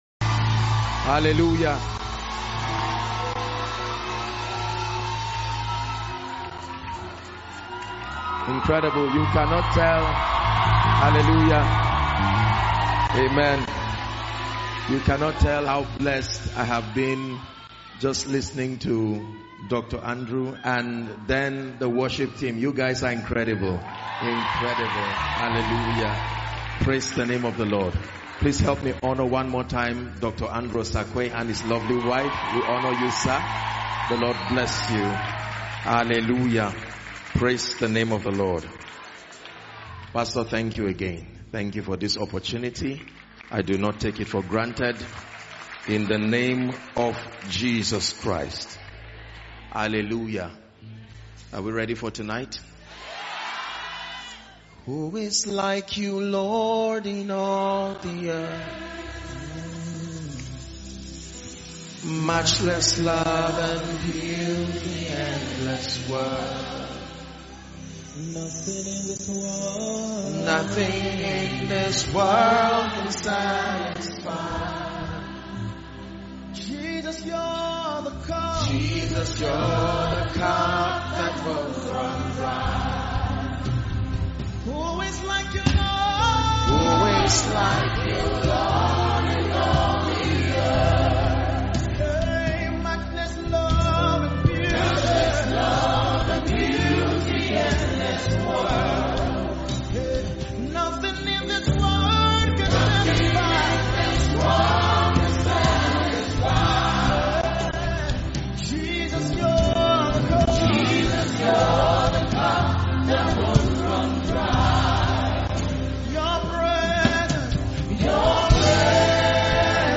Accelerate Conference 2023 _ The Elevation Church